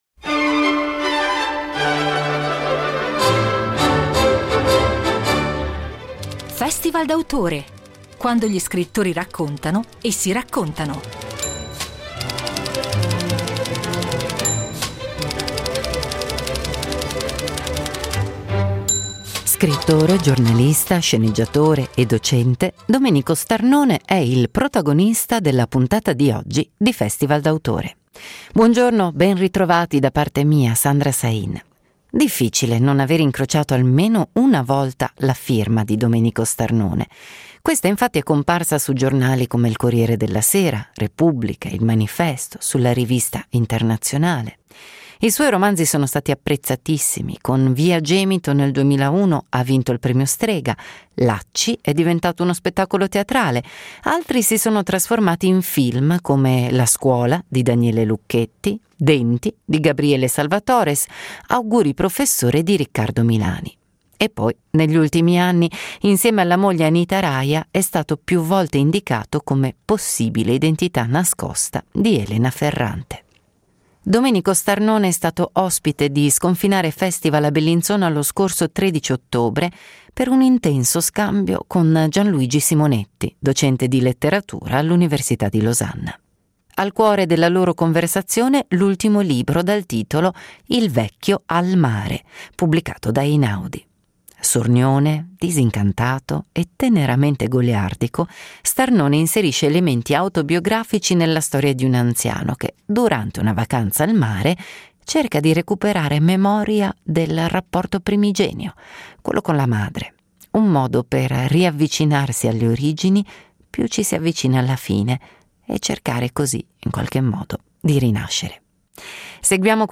Incontro a Sconfinare Festival
Domenico Starnone, Scrittore, giornalista, sceneggiatore e docente, è stato ospite nell’autunno del 2024 dello Sconfinare Festival di Bellinzona .